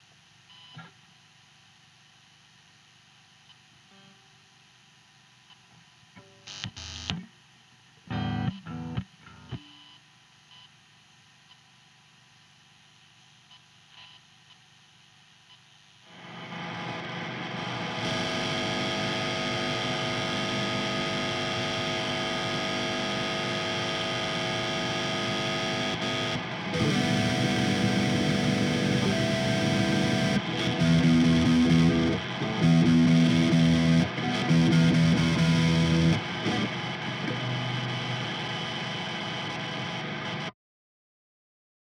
куда копать? подскажите причины шумов, пожалуйста, если возможно их по звуку определить первые 15 секунд без перегруза, потом с максимальным) когда держу пальцы на струнах основной шум пропадает, но остается азбука морзе (слышно на чистом)